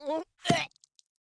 Npc Catspit Sound Effect
npc-catspit-4.mp3